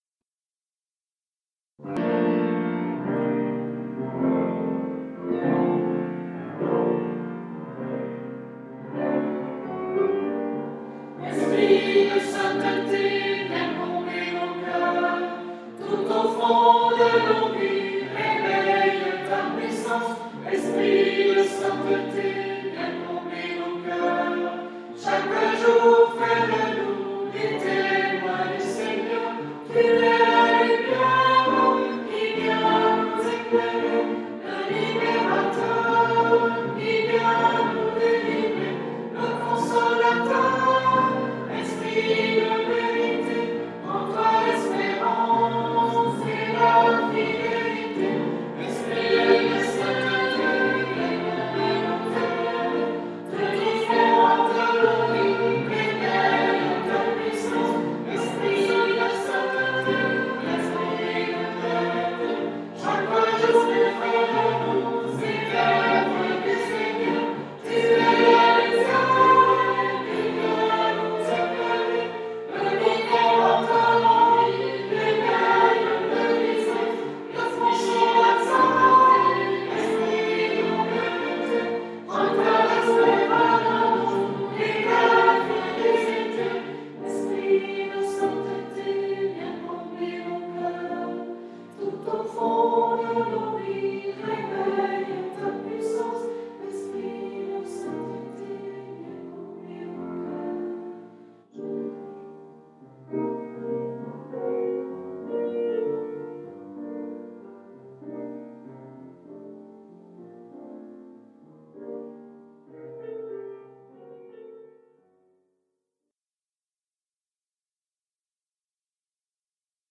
Chant pour la Pentecôte